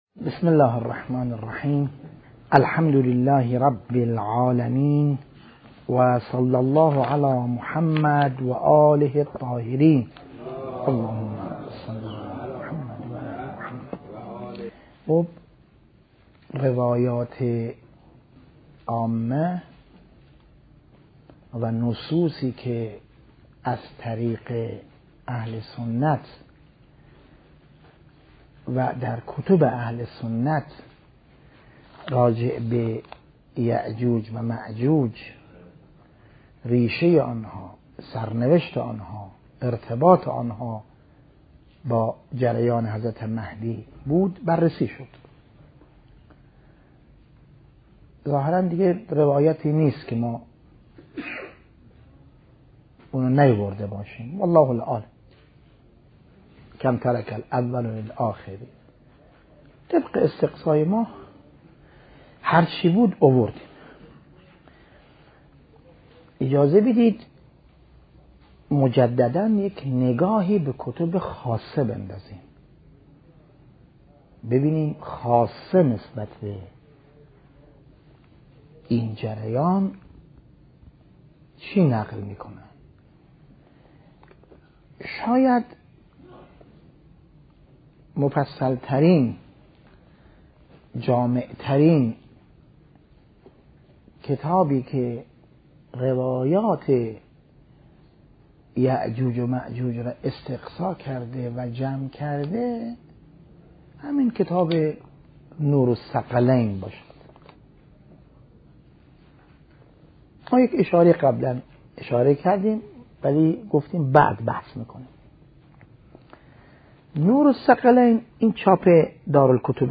بحث خارج مهدویت - یأجوج ومأجوج والسد الموعود ج 9